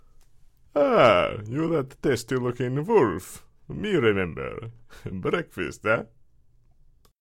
So, today, I thought I’d share a few takes for a few characters I auditioned for.